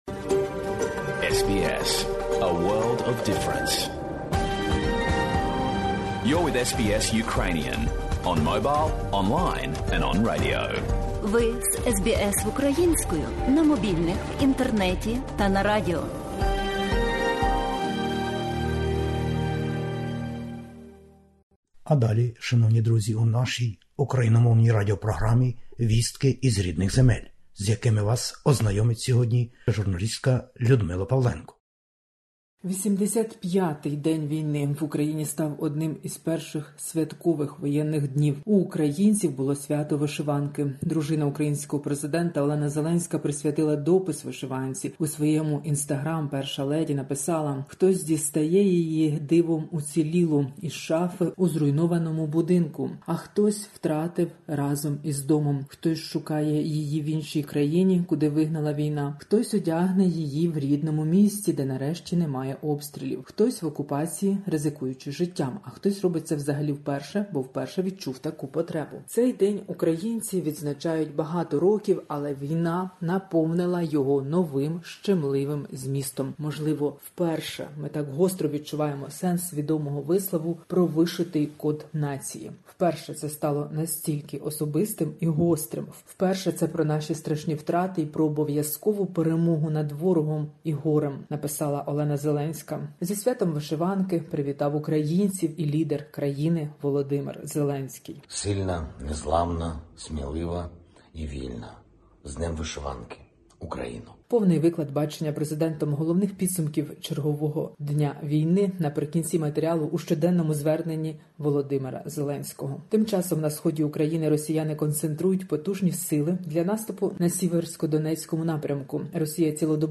Добірка новин із героїчної України спеціально для SBS Ukrainian.